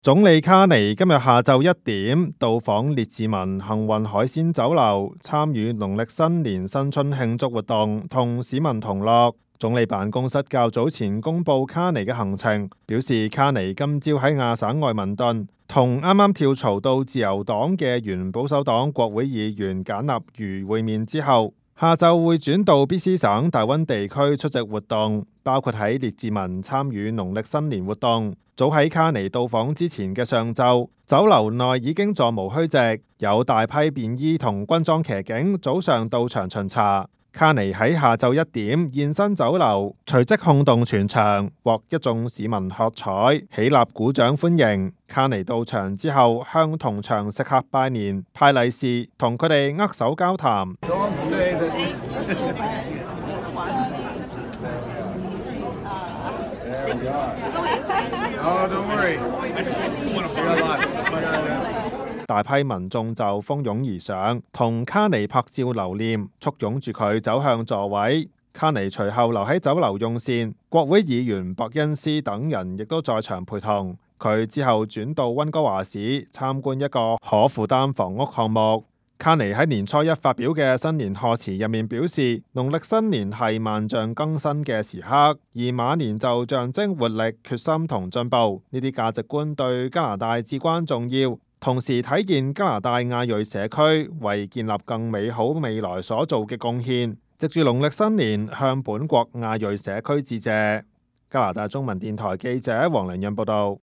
Local News 本地新聞